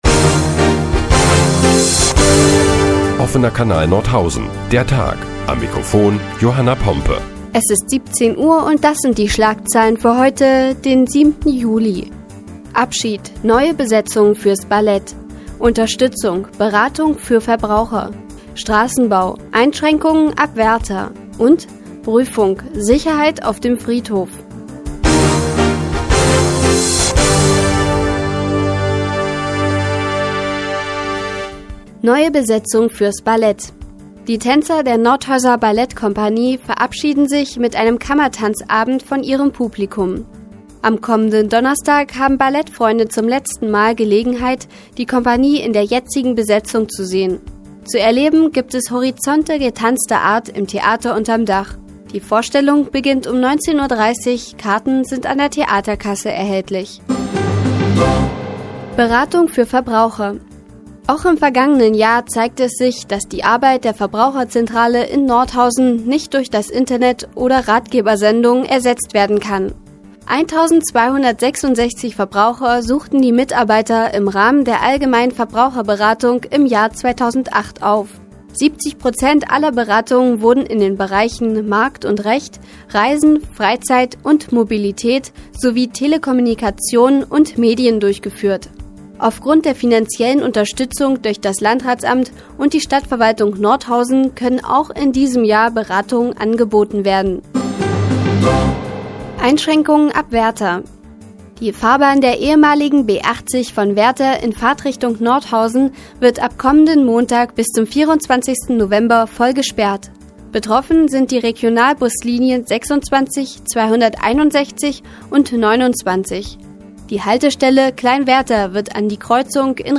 Die tägliche Nachrichtensendung des OKN ist nun auch in der nnz zu hören. Heute geht es unter anderem um die neue Besetzung der Ballettkompanie und mehr Sicherheit auf dem Friedhof.